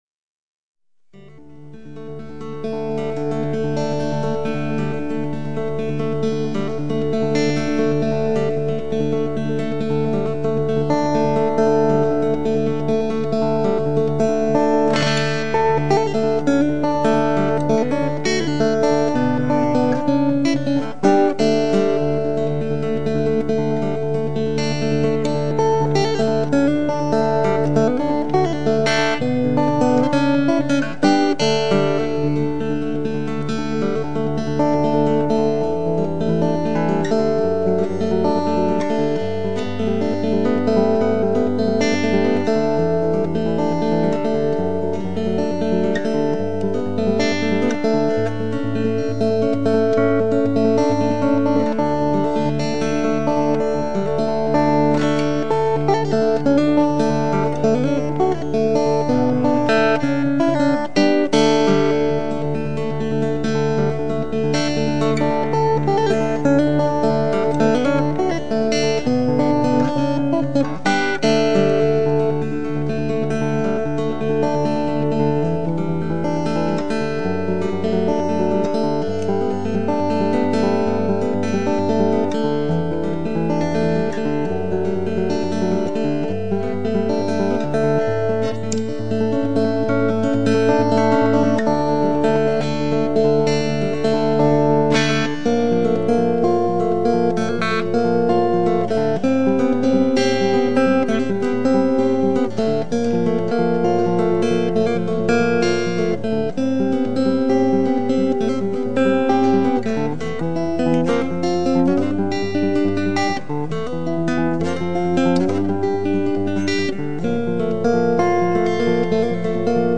Gitarre